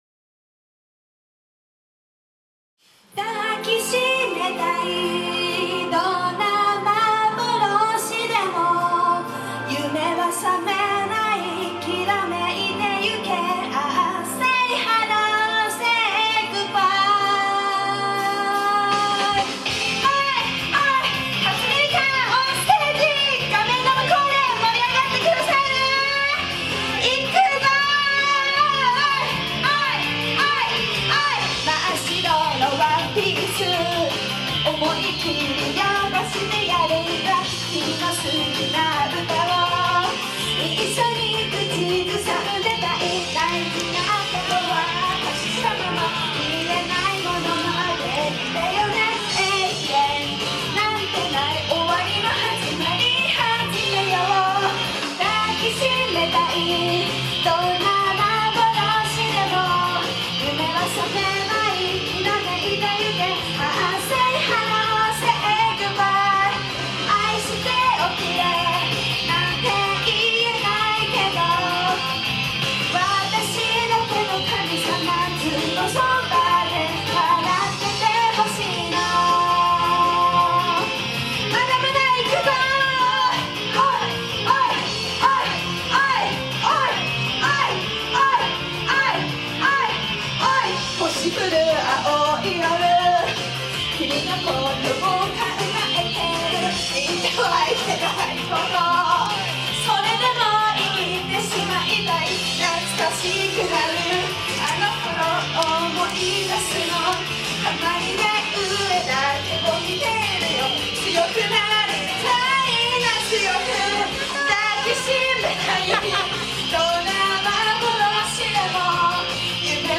Best I can do is the live version.